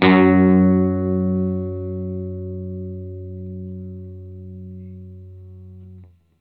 R12 NOTE  FS.wav